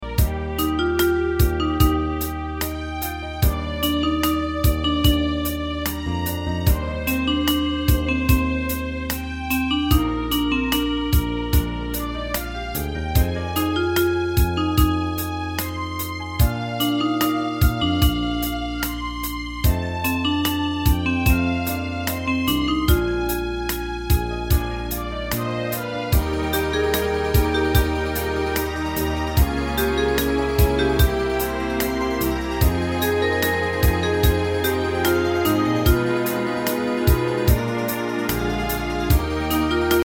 Unison musical score and practice for data.